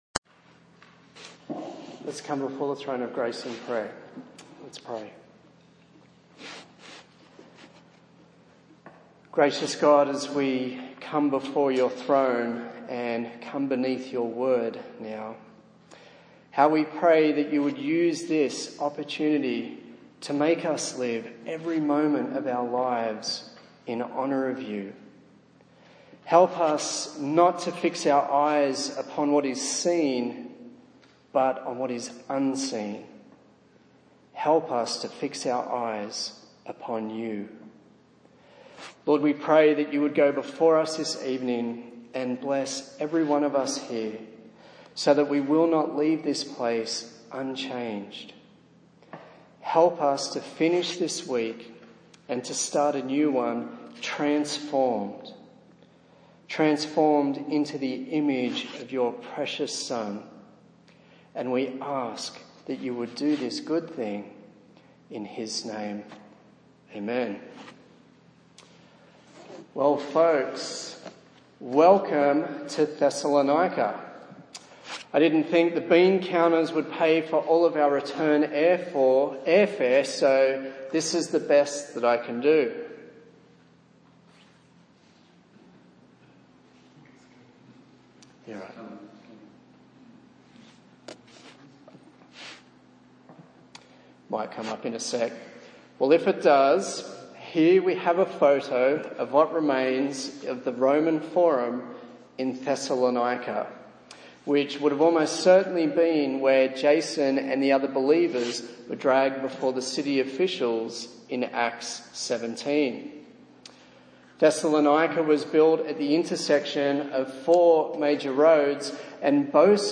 A sermon in the series on the book of 1 Thessalonians